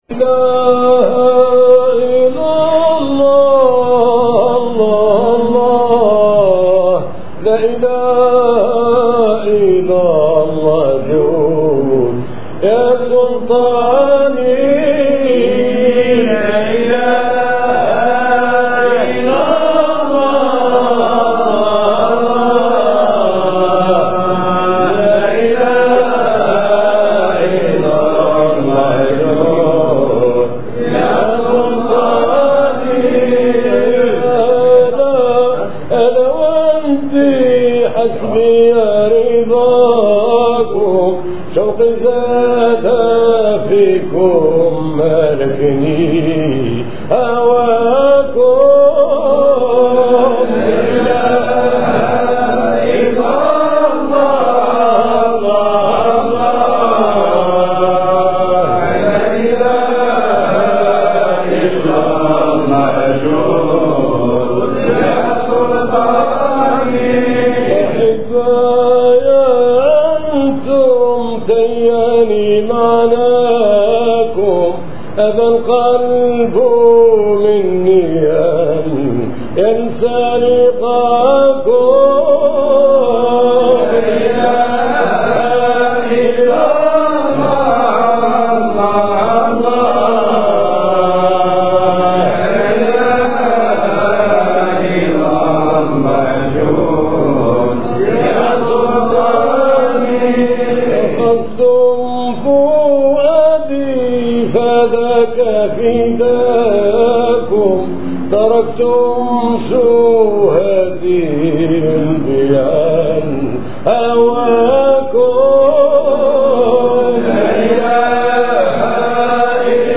موقع التّصوّف الإسلامي و طريقة القطب الشامل الشّيخ احمد العلاوي قدّس الله سرّه : سماع